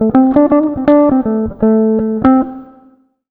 160JAZZ 10.wav